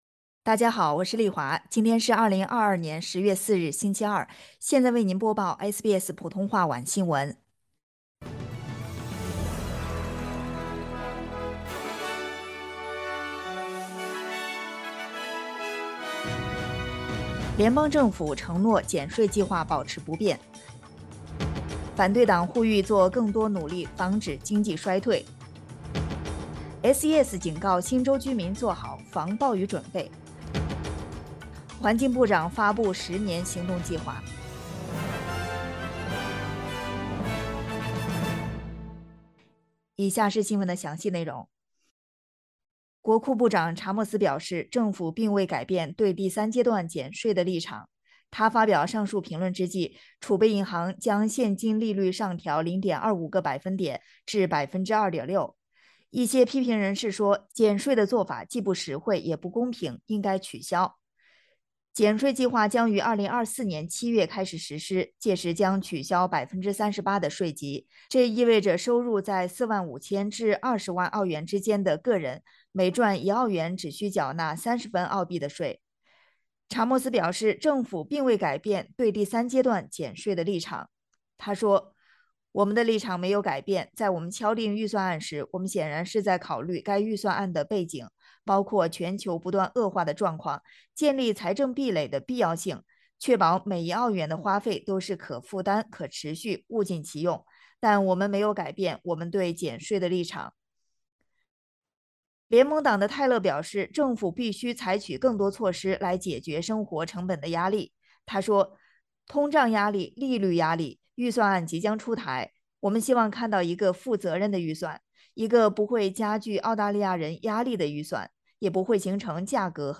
SBS晚新闻（2022年10月4日）